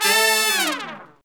Index of /90_sSampleCDs/Roland L-CDX-03 Disk 2/BRS_R&R Horns/BRS_R&R Falls